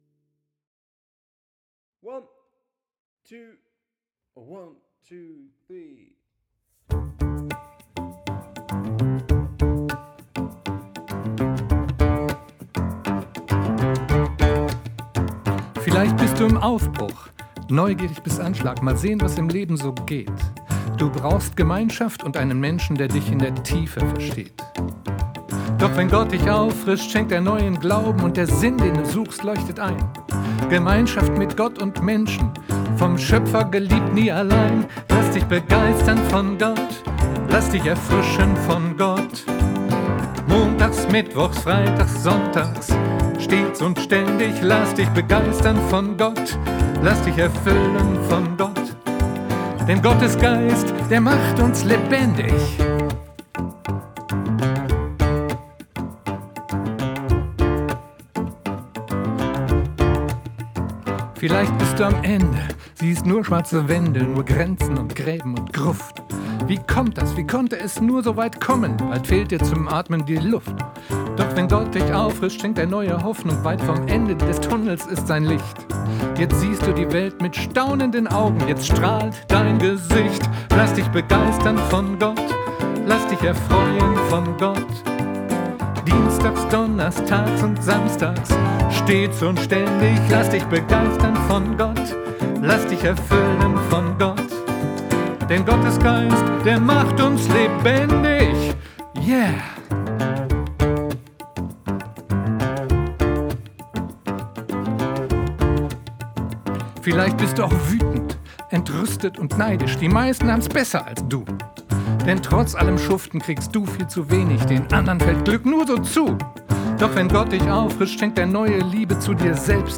Ein etwas anderer Musikstil als meine anderen Lieder :-)...